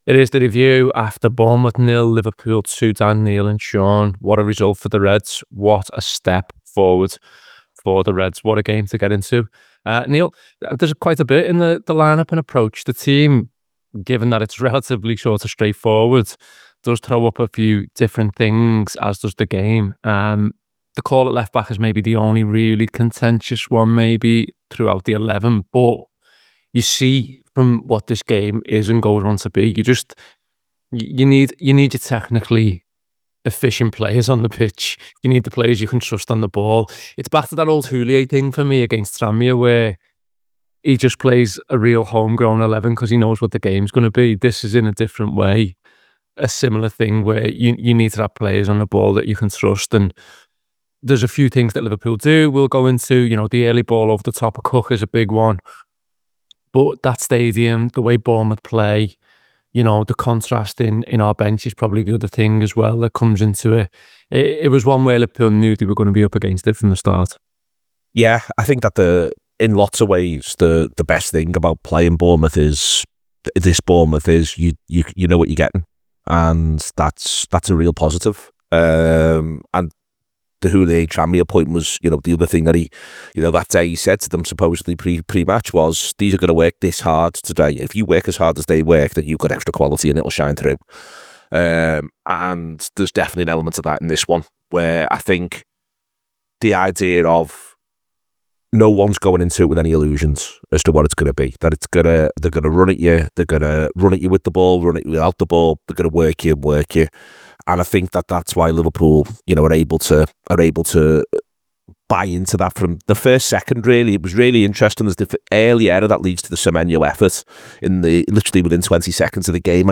Below is a clip from the show- subscribe for more review chat around Bournemouth 0 Liverpool 2…